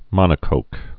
(mŏnə-kōk, -kŏk)